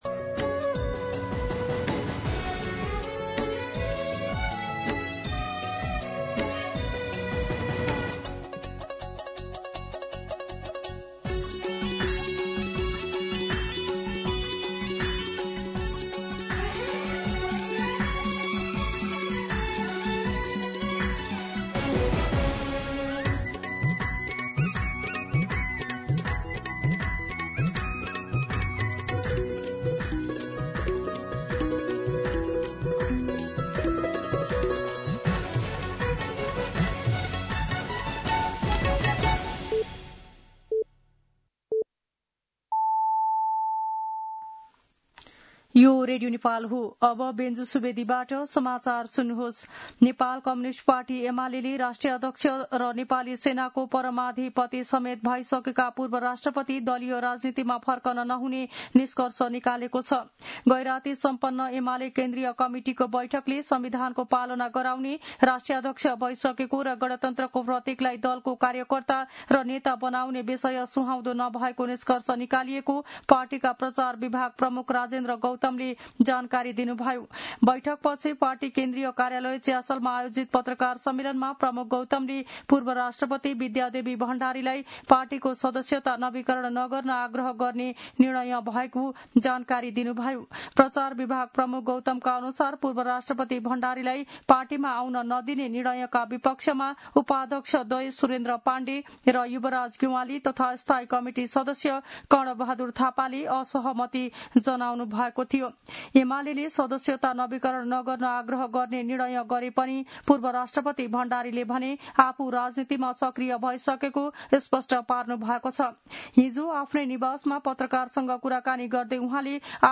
मध्यान्ह १२ बजेको नेपाली समाचार : ७ साउन , २०८२